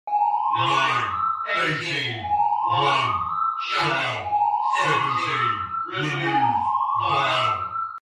На этой странице собраны звуки сиреноголового — жуткие аудиозаписи, создающие атмосферу страха и неизвестности. Здесь вы найдете крики, скрипы, шаги и другие эффекты, которые помогут погрузиться в мистическую атмосферу.